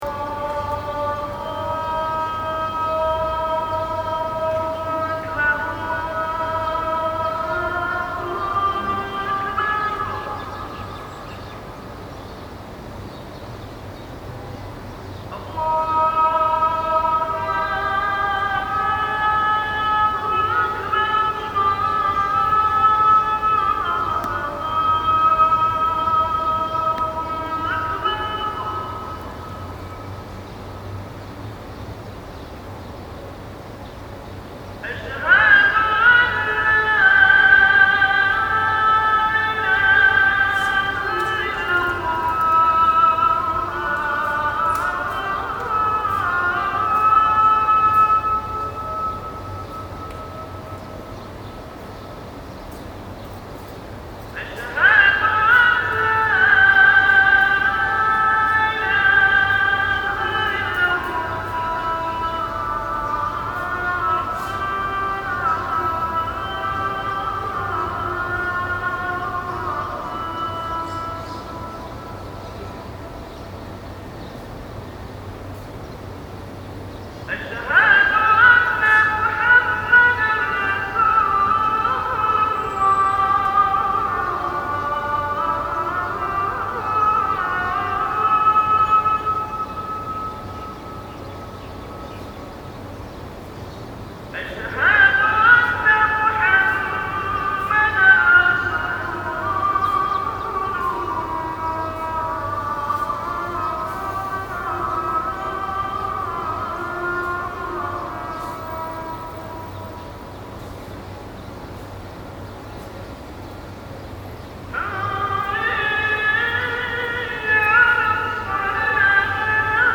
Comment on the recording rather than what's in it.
This is from yesterday evening, recorded on my laptop in a parking lot near a mosque.